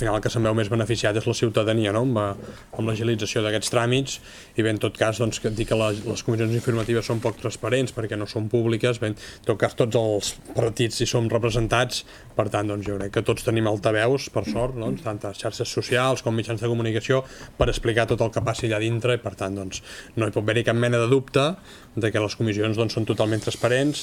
Marc Buch, alcalde: